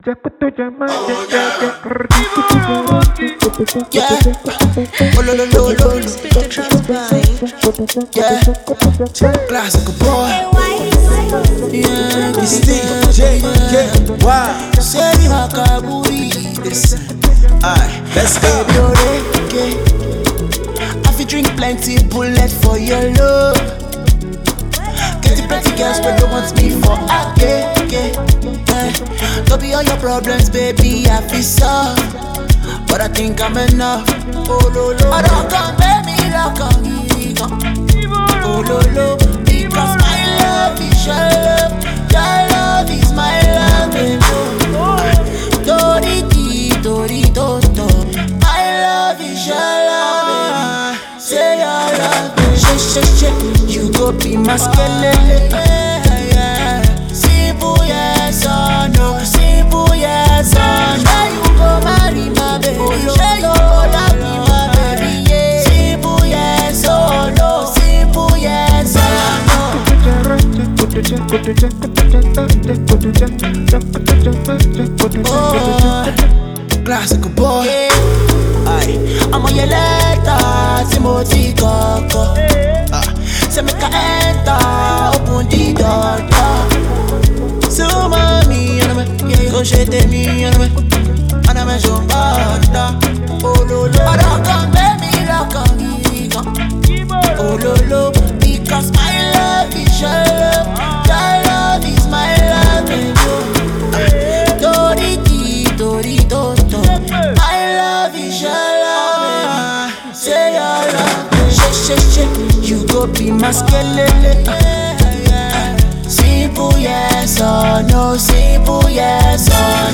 is a classic tune infused with more catchy lyrics
delivers in his usual energetic style